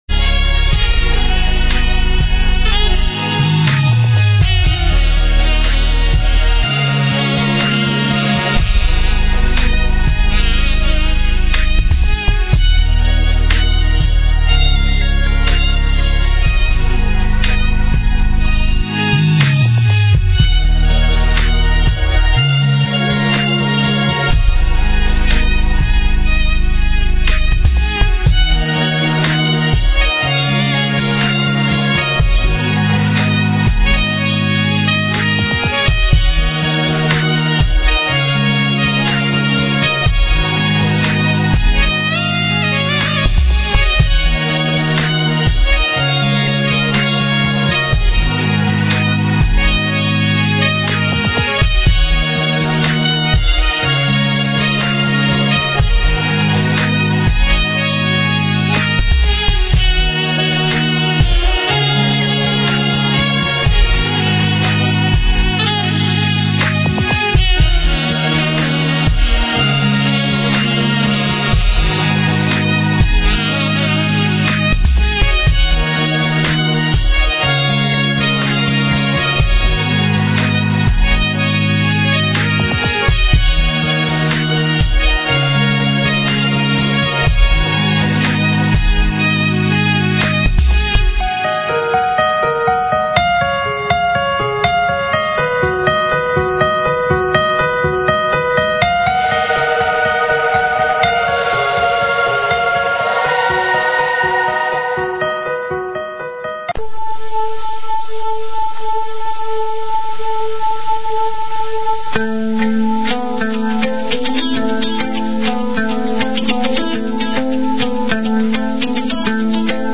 Talk Show Episode, Audio Podcast, UntoldMysteries and Courtesy of BBS Radio on , show guests , about , categorized as